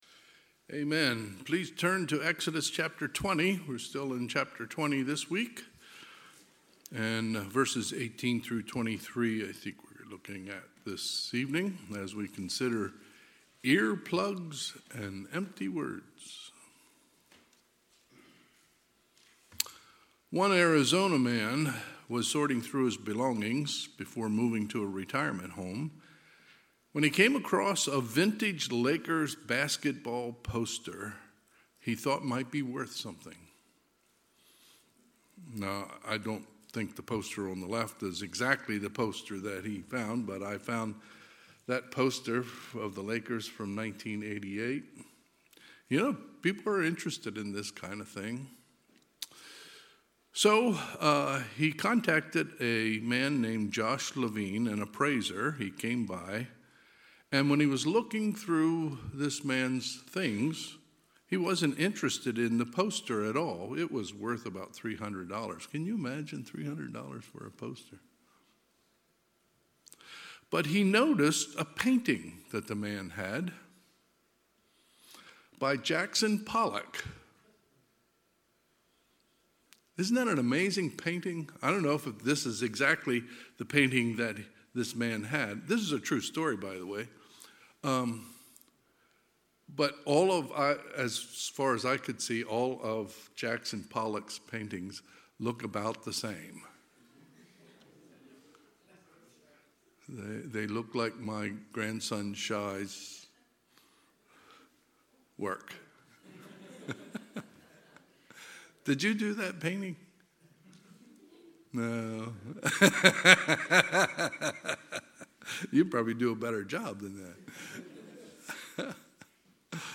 Sermons | North Hills Bible Church | Page 15